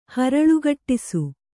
♪ haraḷugaṭṭisu